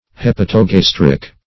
Search Result for " hepatogastric" : The Collaborative International Dictionary of English v.0.48: Hepatogastric \Hep`a*to*gas"tric\, a. [Hepatic + gastric.]
hepatogastric.mp3